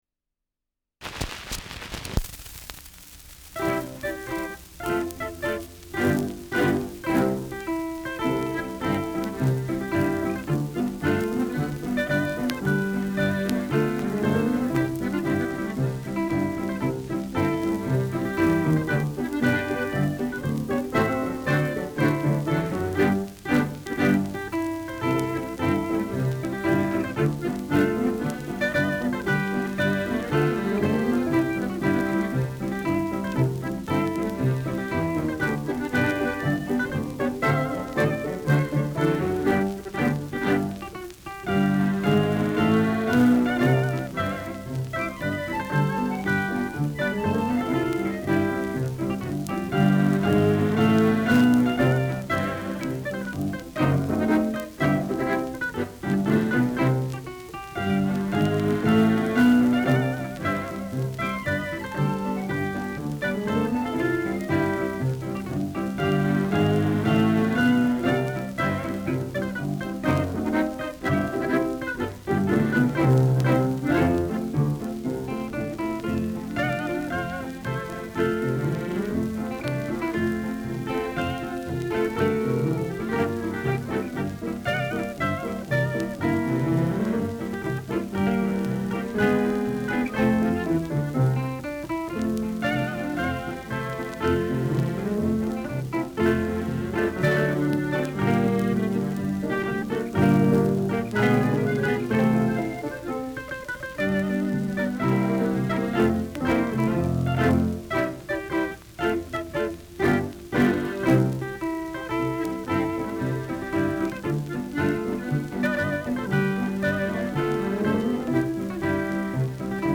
Schellackplatte
Stärkeres Grundrauschen : Vereinzelt leichtes Knacken